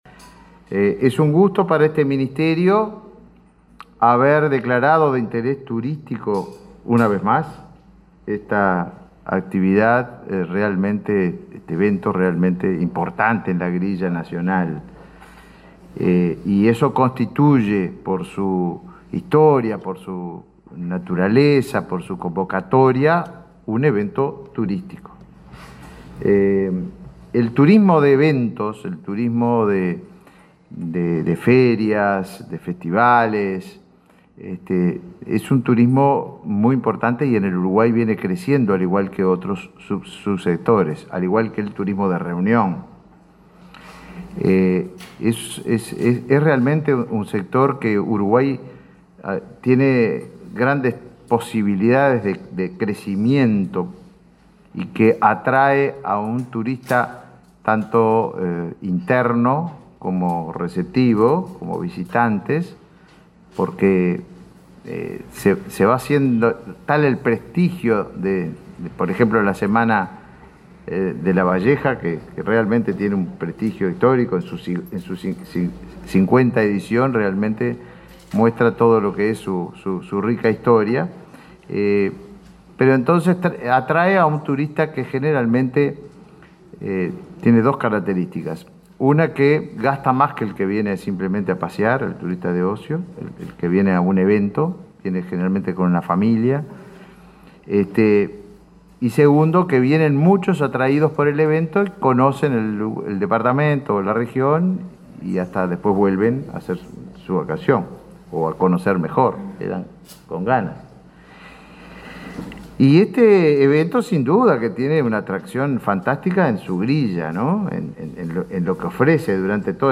Palabras de autoridades en el Ministerio de Turismo
Palabras de autoridades en el Ministerio de Turismo 03/10/2023 Compartir Facebook X Copiar enlace WhatsApp LinkedIn Este martes 3, en Montevideo, el ministro de Turismo, Tabaré Viera, y el secretario de la Presidencia, Álvaro Delgado, participaron en el acto de lanzamiento de la 50.ª edición de la Semana de Lavalleja y la Noche de los Fogones.